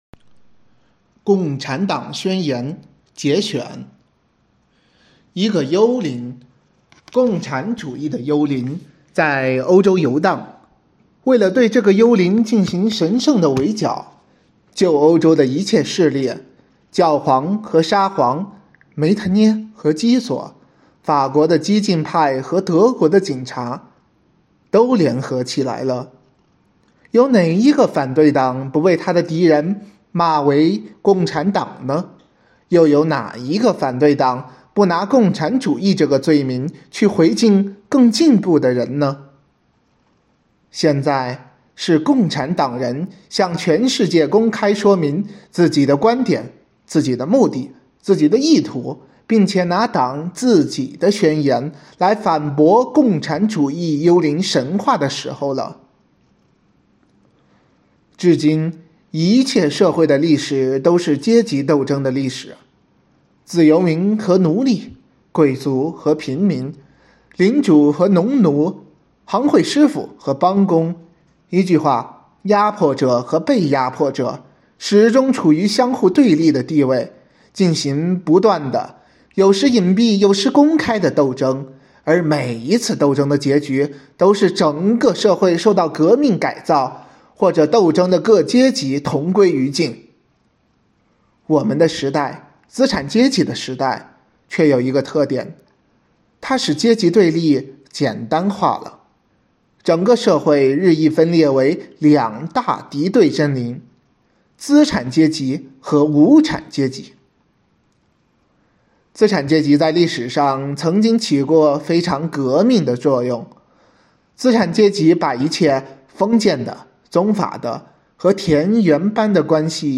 生活好课堂幸福志愿者中国钢研朗读服务（支）队第十一次云朗诵会在五月开启，声声朗诵、篇篇诗稿赞颂红五月，讴歌美好生活，吟诵美丽中国。
《共产党宣言》（节选）朗诵